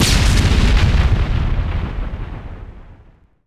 GrenadeExplode.wav